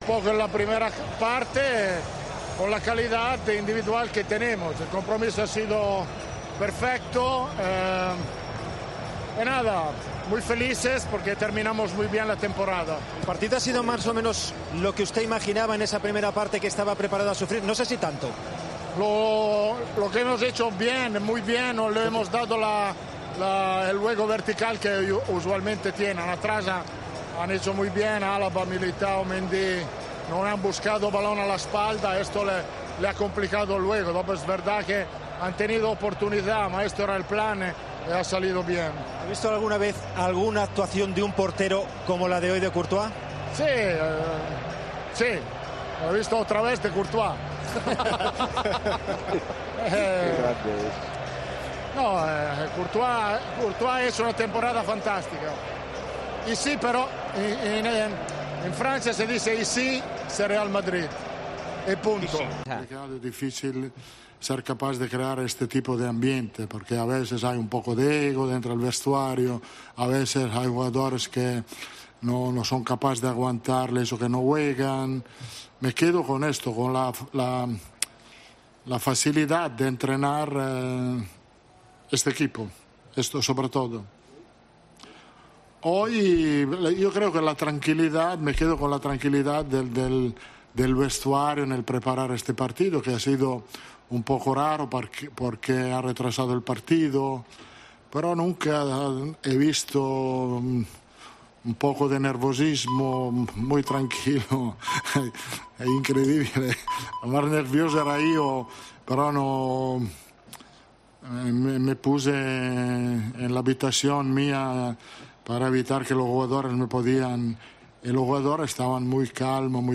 El preparador italiano afirmó antes del acto protocolario de la entrega del trofeo sobre el césped del Estadio de Francia en declaraciones a Movistar que merecieron el triunfo "sufriendo un poco en la primera parte" y aseguró que "el compromiso (de sus jugadores) ha sido perfecto".